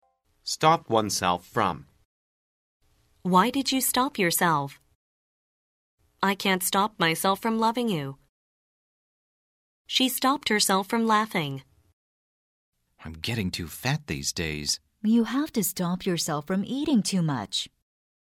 通过生动的对话说明短语的实际表达用法，8000多句最实用的经典表达，保证让你讲出一口流利又通顺的英语，和老外聊天时再也不用担心自己的英语错误百出了！